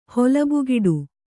♪ holabugiḍu